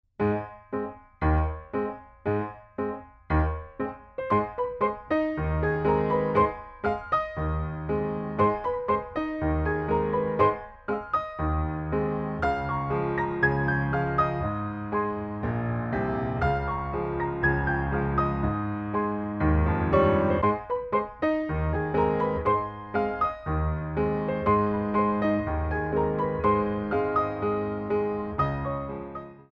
4 Count introduction included for all selections
2/4 - 64 with repeat